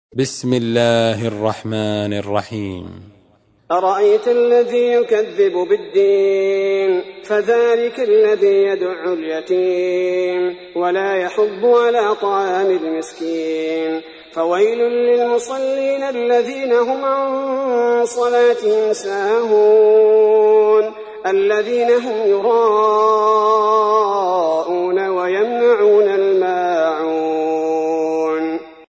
تحميل : 107. سورة الماعون / القارئ عبد البارئ الثبيتي / القرآن الكريم / موقع يا حسين